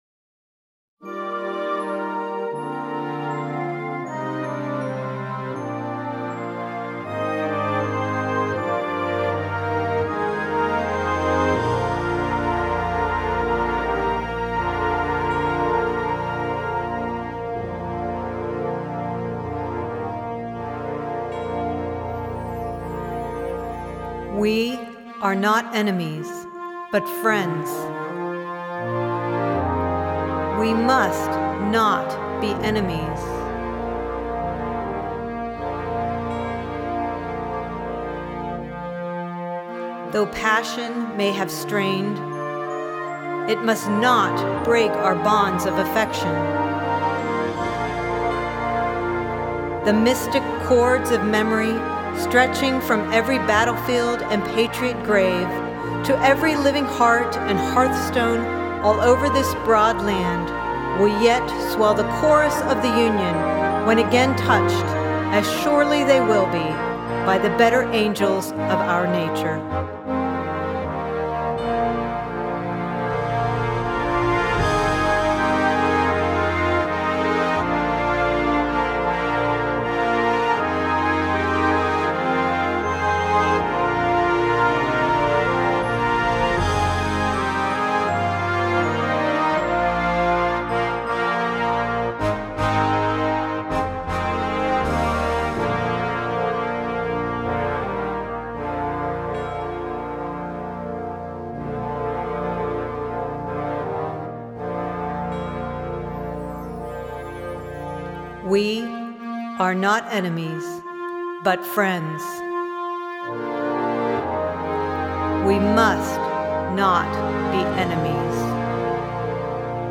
Voicing: Concert Band and Narrator